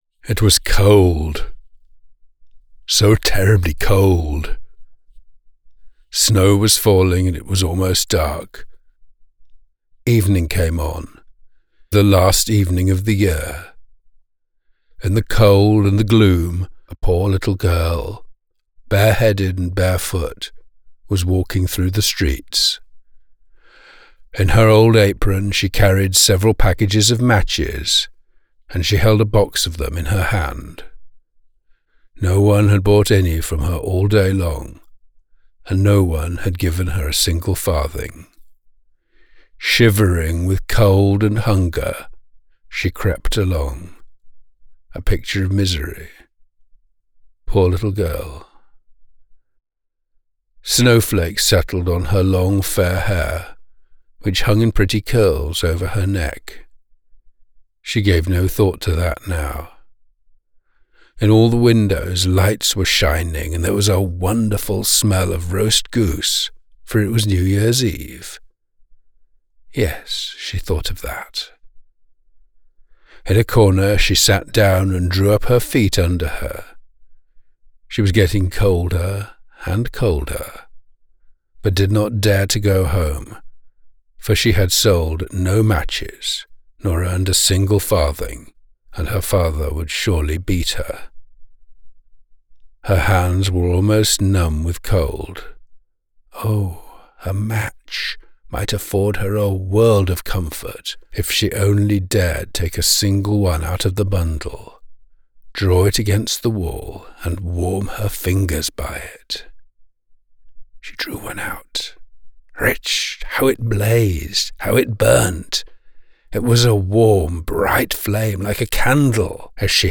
British Children's Book Narrator: